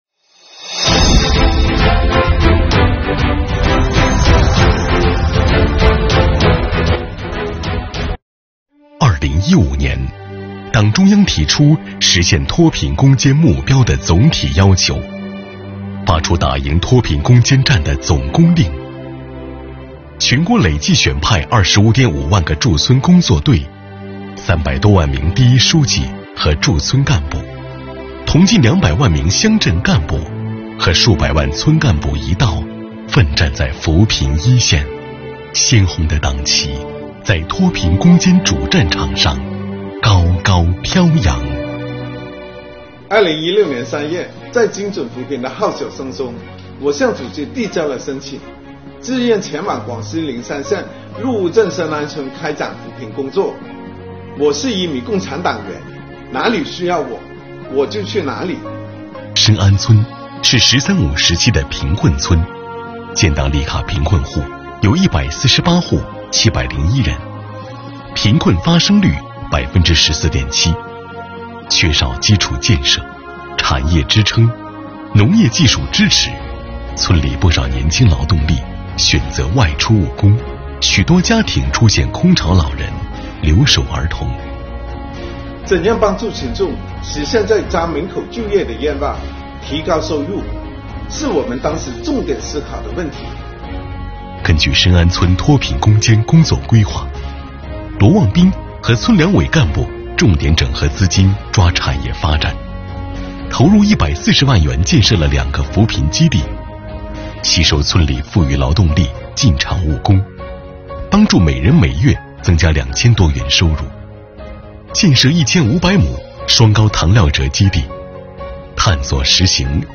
为推动党史学习教育深入群众、深入基层、深入人心，广西税务局组织推出“党员讲税事”专栏，通过税务先锋党员讲党史故事、讲税收故事，引领广大党员干部在“有深度、接地气”的党史学习教育中感悟党的初心使命，激发广大党员干部奋斗“十四五”、奋进新征程的磅礴力量。